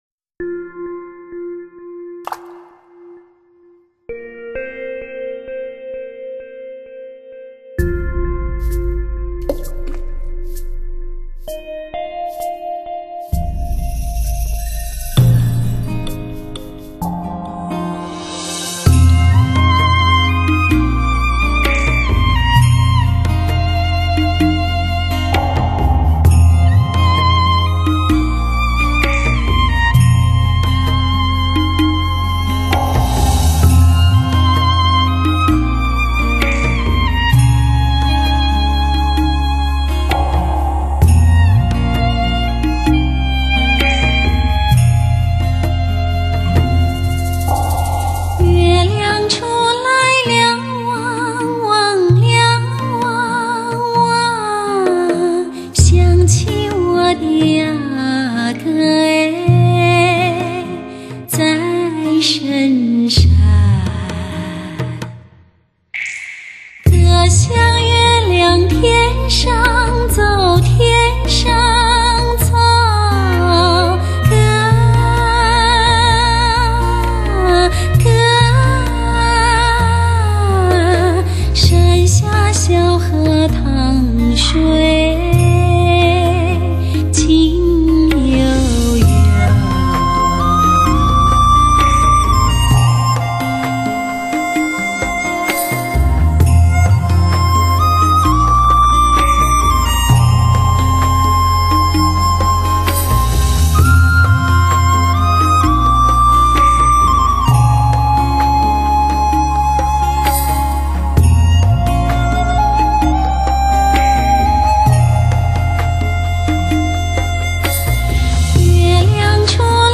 歌者亦如同一把感染力丰富的器乐，与其它的乐声一起构成我们聆听的自然。